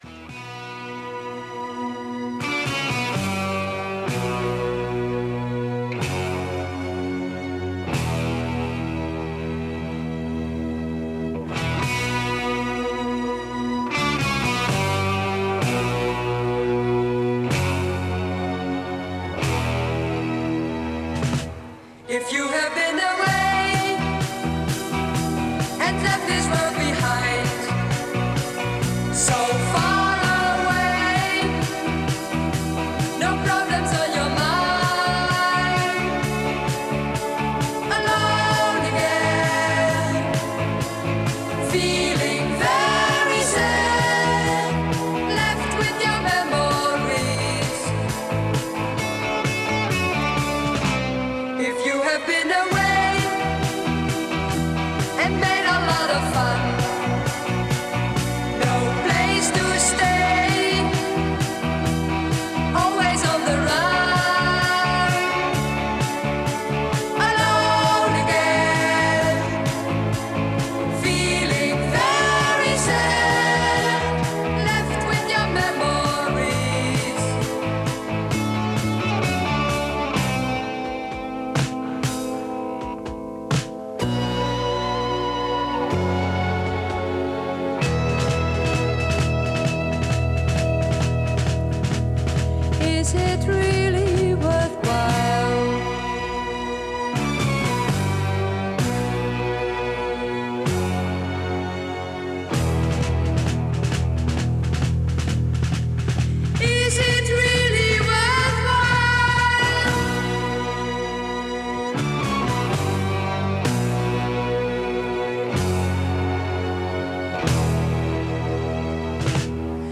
хор и орк.